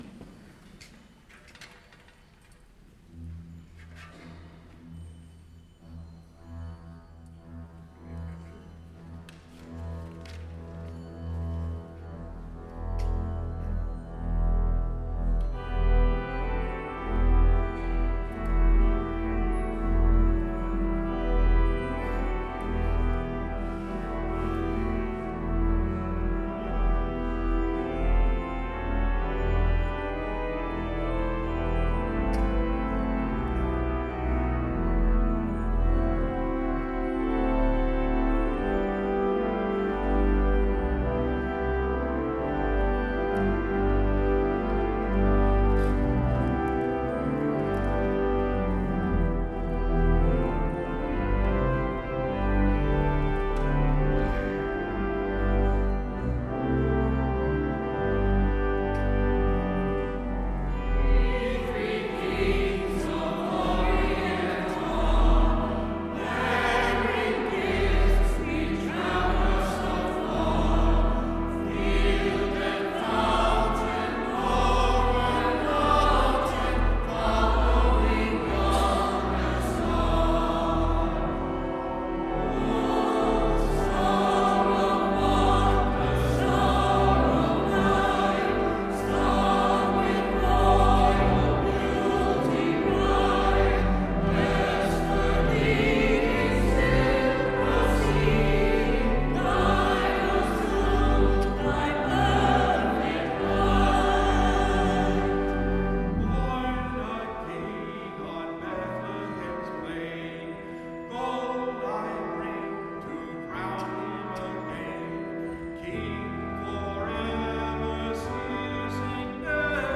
Lessons and Carols 2010
Our Lady of Mount Carmel Church, Newark New Jersey